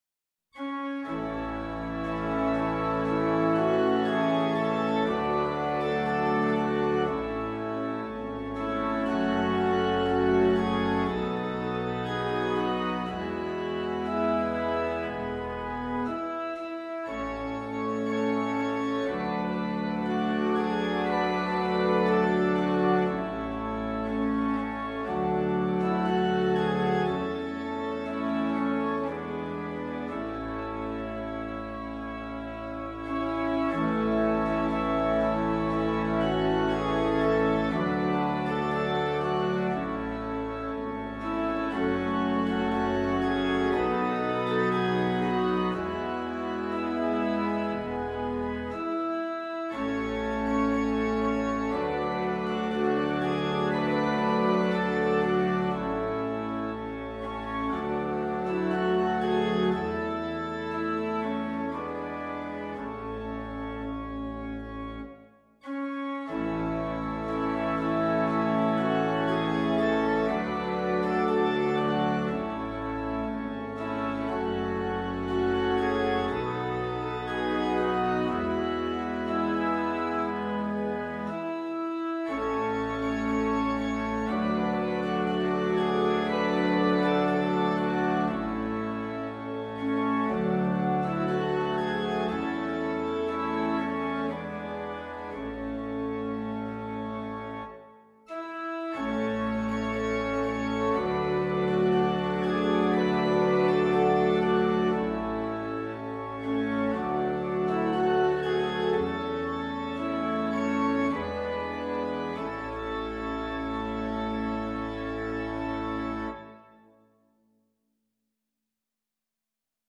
Classical Merikanto, Oskar Dar bjorkarna susa Organ version
A major (Sounding Pitch) (View more A major Music for Organ )
4/4 (View more 4/4 Music)
~ = 100 Adagio
Organ  (View more Easy Organ Music)
Classical (View more Classical Organ Music)